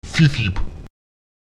Lautsprecher cipcip [ČTiTip] sehr schnell